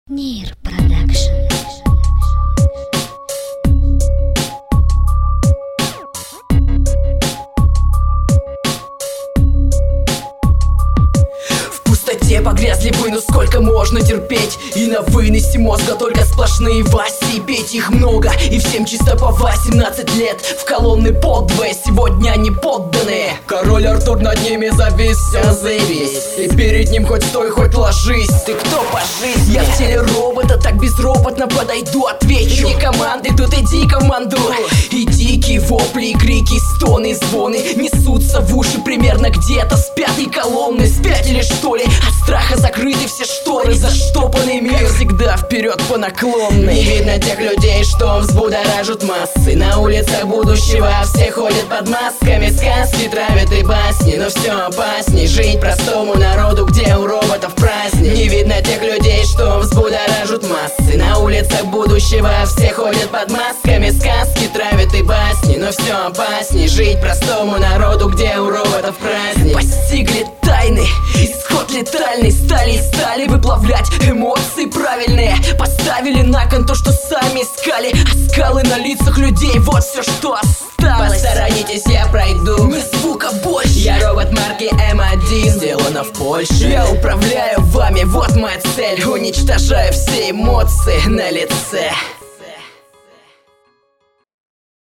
Читаешь неплохо, но слушается скучновато (чисто моё мнение)
Минусок крутой.
Подача не понравилась совсем, но объективно неплоха благодаря куче интонаций....